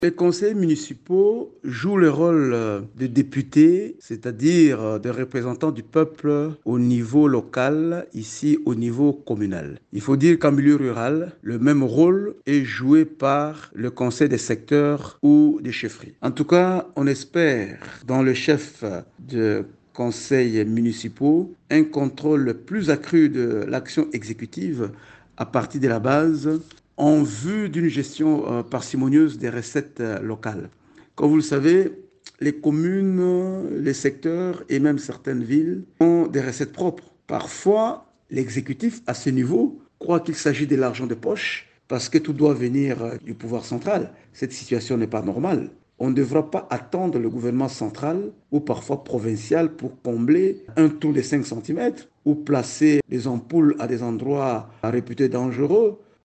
Vous pouvez écouter l’argumentaire de Gary Sakata dans cet extrait sonore :